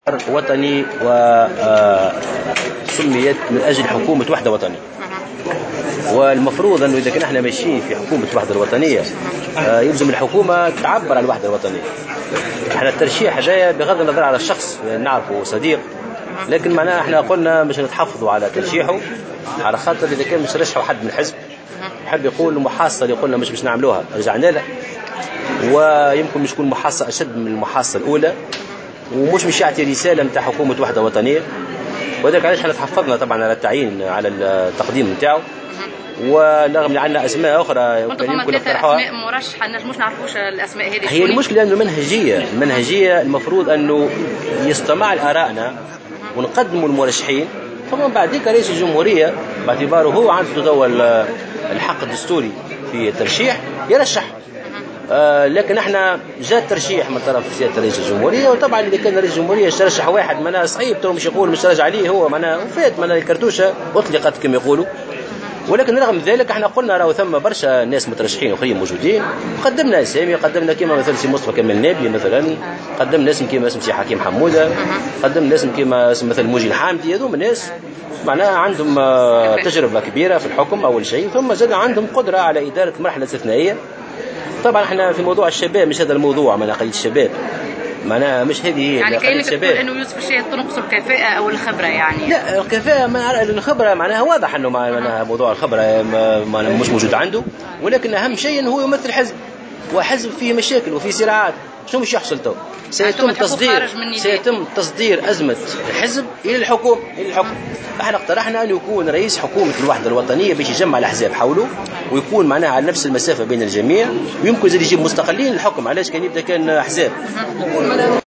وكشف في تصريحات صحفية بمناسبة تواجده في ولاية المنستير لزيارة مقبرة آل بورقيبة، أن حزبه اقترح كل من مصطفى كمال النابلي وحكيم حمودة ومنجي الحامدي لشغل هذا المنصب، لكن رئيس الجمهورية استبق بيوسف الشاهد معلقا بالقول: الرصاصة اطلقت"، بحسب تعبيره.